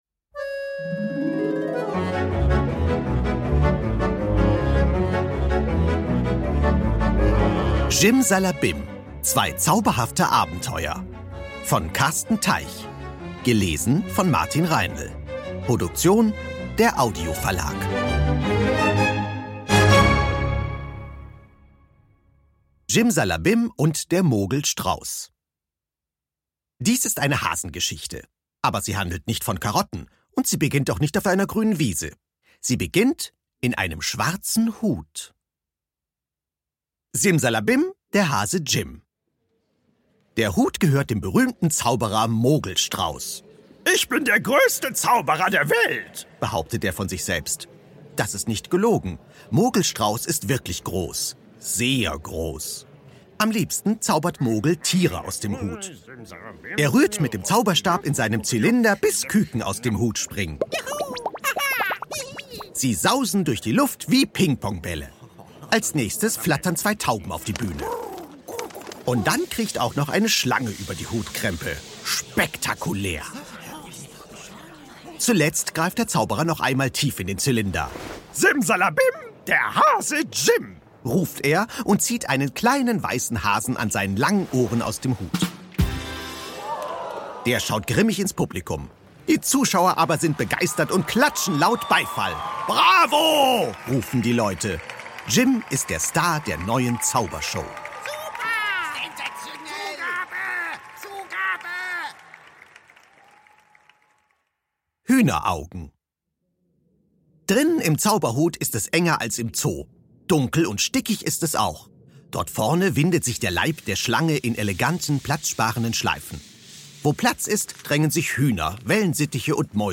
Ungekürzte szenische Lesungen mit Musik mit Martin Reinl (1 CD)
Martin Reinl (Sprecher)
Ungekürzte szenische Lesung mit Musik mit Martin Reinl